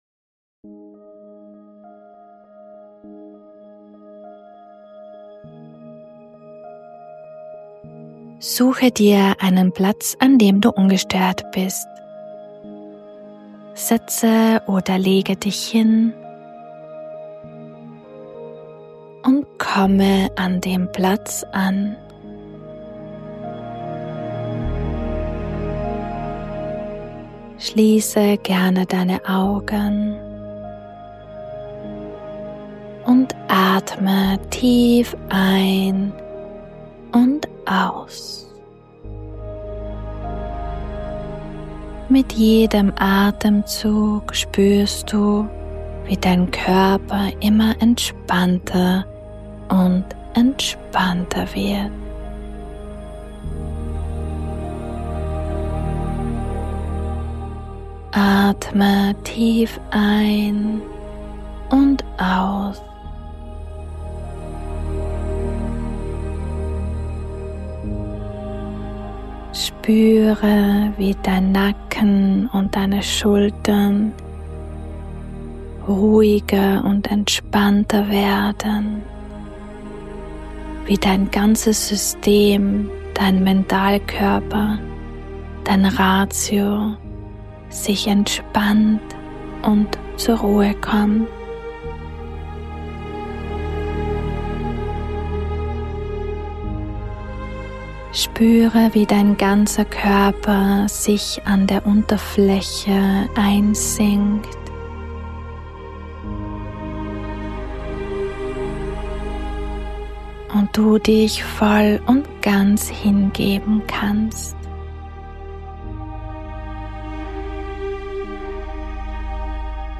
Diese Meditation kann dich dabei unterstützen alte Geschichten und nicht-dienliche Glaubenssätze, Versionen, Erfahrungen, Prägungen und/oder Verbindungen los- und freizulassen.